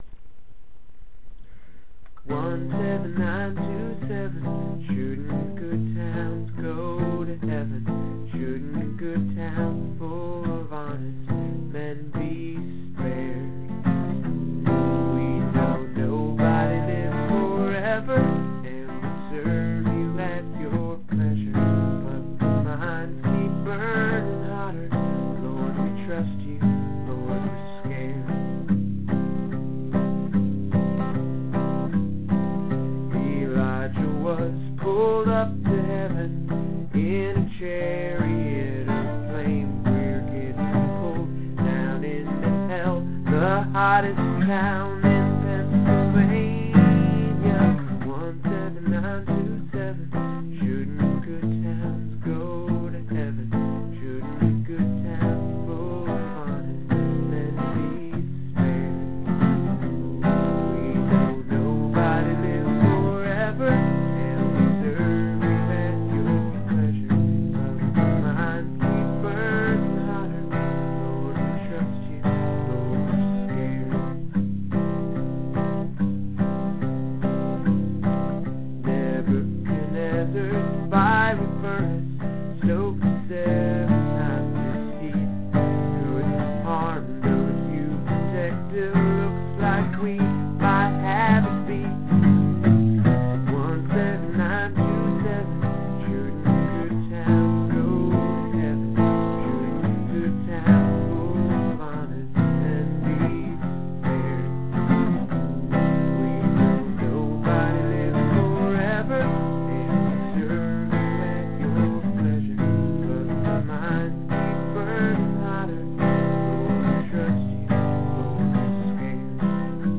17927 (demo) - MP3